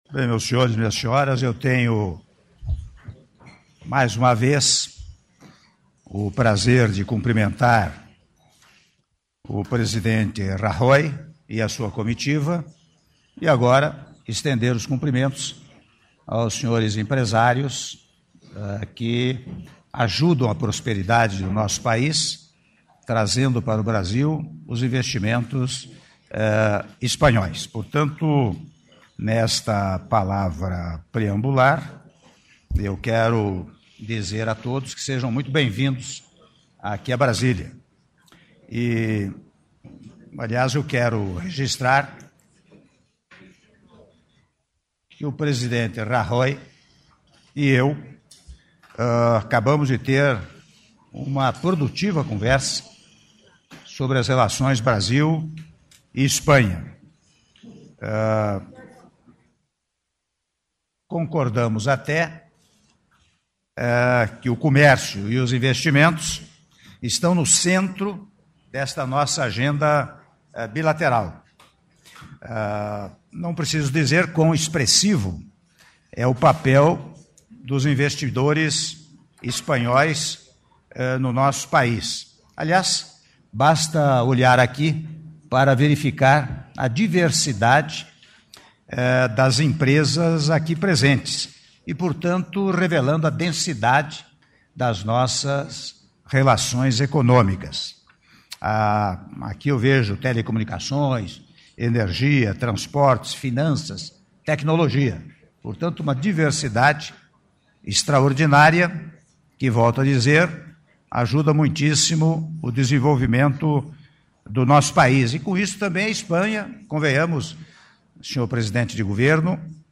Áudio do discurso do Presidente da República, Michel Temer, durante Reunião Bilateral com o Presidente do Governo da Espanha, Mariano Rajoy - (05min30s) - Brasília/DF